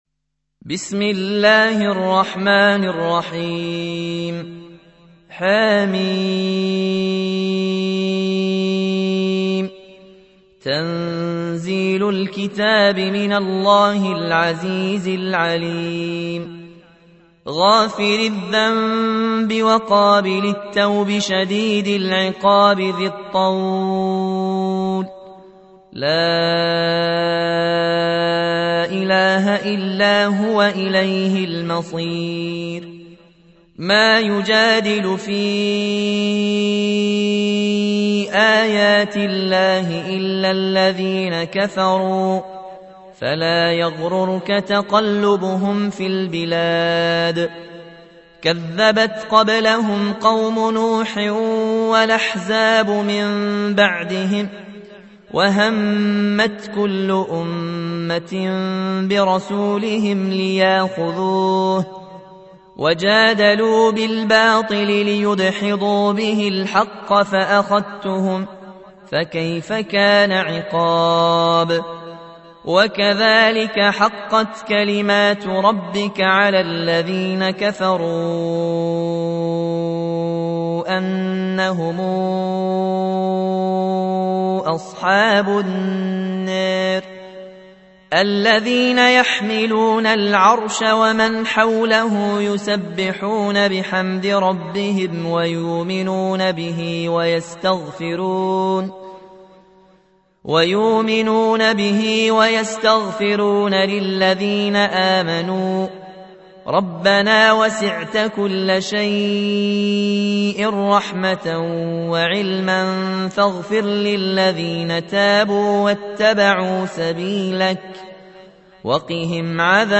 تحميل : 40. سورة غافر / القارئ ياسين الجزائري / القرآن الكريم / موقع يا حسين